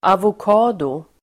Ladda ner uttalet
avokado substantiv, avocado (pear) Uttal: [avok'a:do] Böjningar: avokadon, avokador Definition: en tropisk frukt (a tropical fruit) avocado substantiv, avokado , avocado Förklaring: a *tropical fruit (en tropisk frukt)